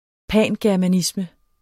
Udtale [ ˈpæˀngæɐ̯maˌnismə ]